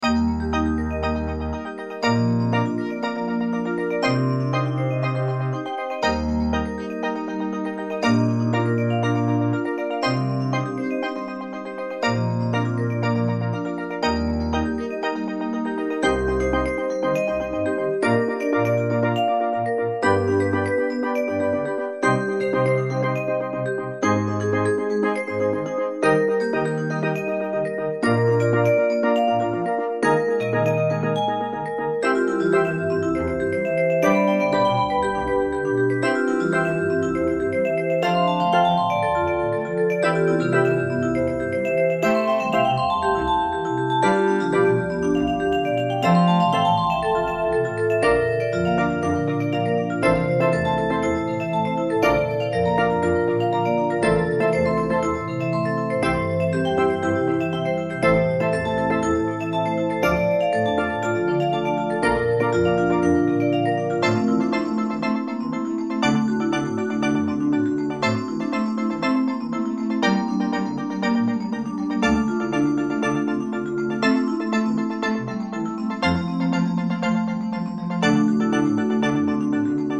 Fx3(クリスタル)、コントラバス、スティールドラム